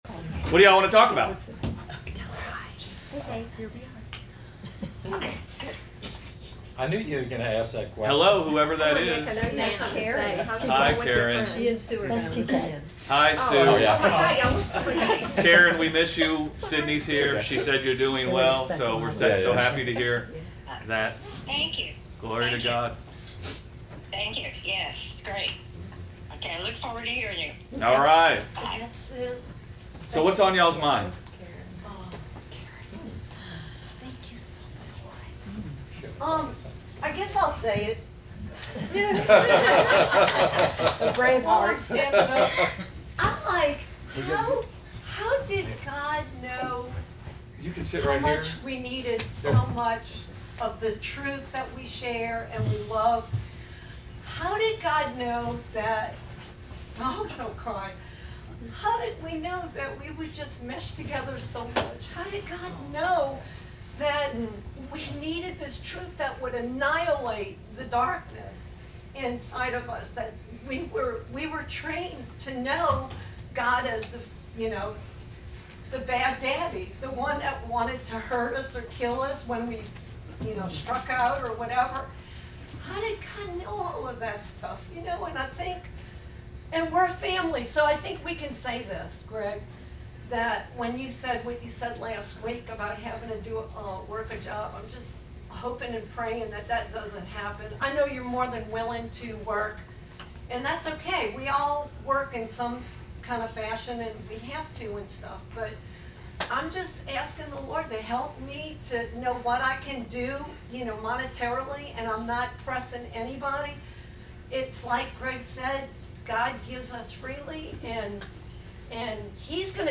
Sunday Bible Study at Gospel Revolution Church where we discuss Beholding God